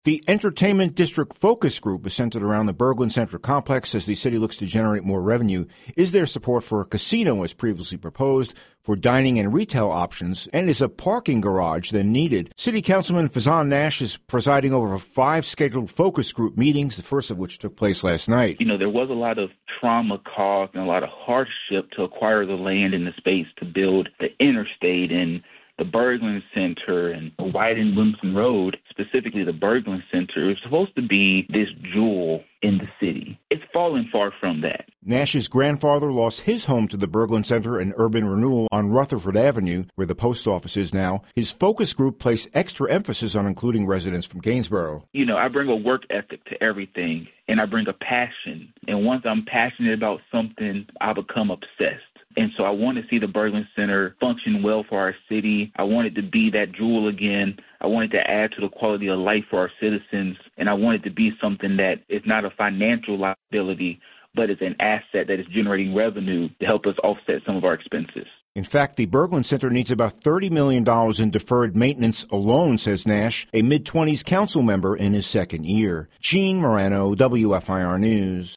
In fact the Berglund Center needs about 30 million in deferred maintenance alone says Nash, a mid-20’s council member in his second year. The story